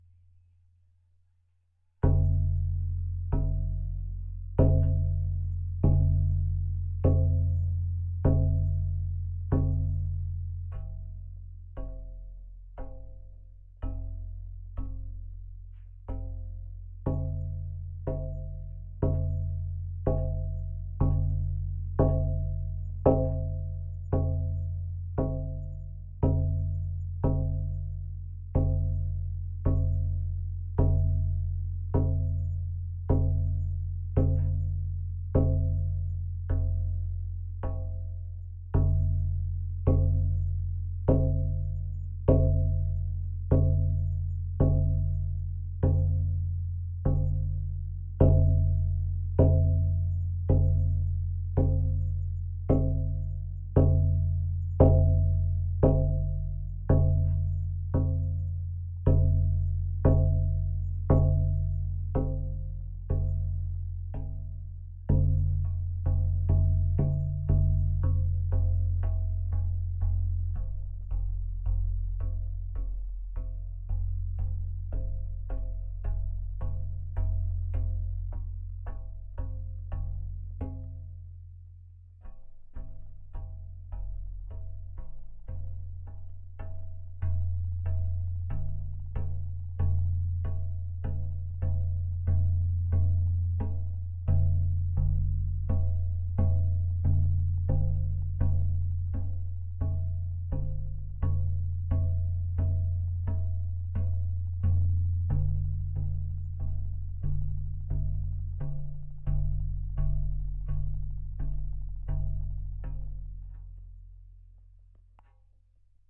描述：街道上的氛围，有一个行进中的乐队，人们在交谈，还有一只狗在吠叫。在我的阳台上用Rode NT4在iRiver H120上录制
Tag: 氛围 吠叫 城市 现场记录 踏着带